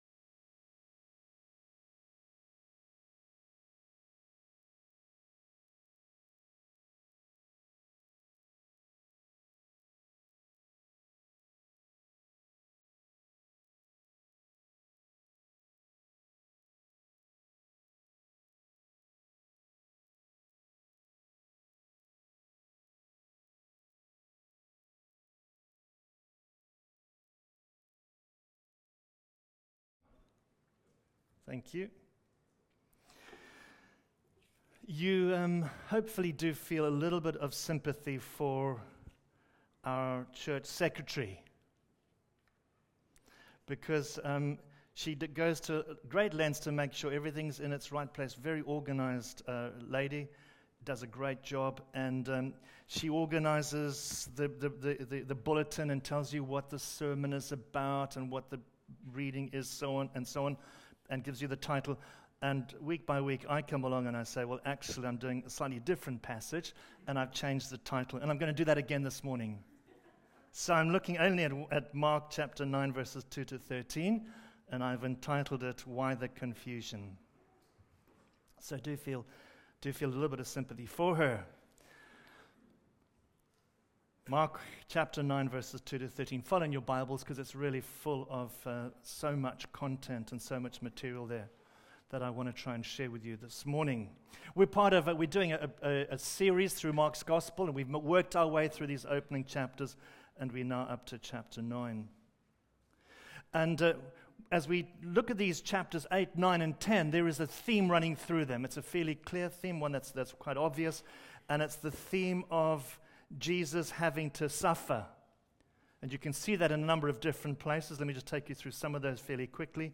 Bible Talks Bible Reading: Mark 9:2-13